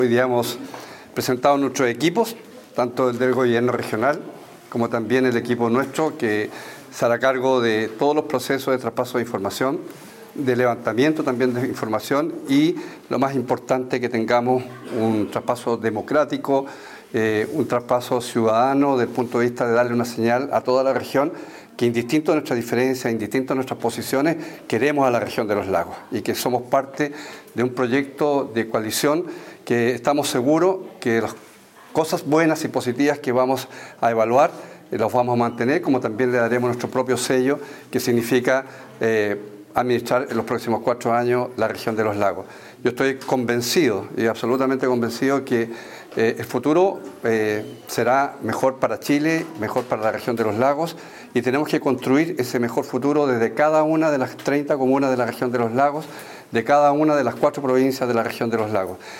Por su parte, el Gobernador Regional Electo, Alejandro Santana expresó que este proceso busca el traspaso de información para la futura gestión, dando un claro mensaje a la comunidad de que a pesar de las diferencias políticas, se busca el bienestar de la Región de Los Lagos.